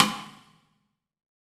HEX STICK.wav